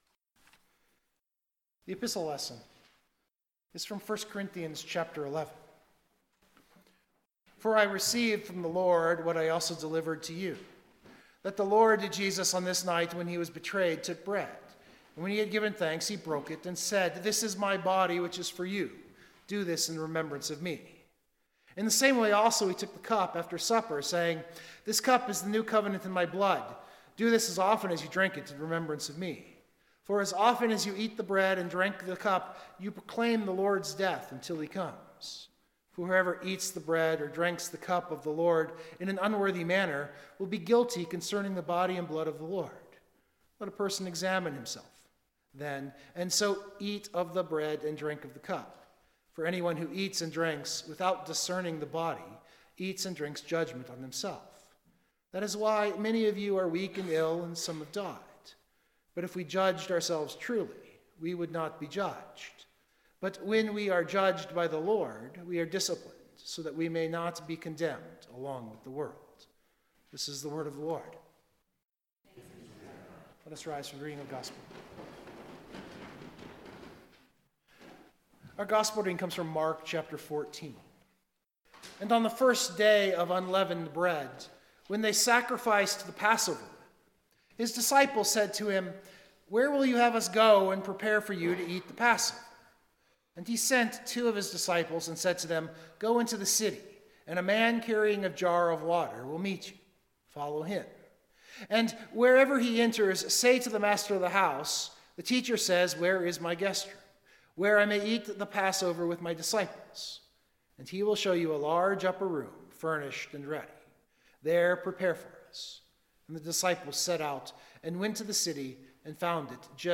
Biblical Text: Luke 17:1-10 Full Sermon Draft
I did not include any of the hymns today primarily because the recording quality wasn’t quite there.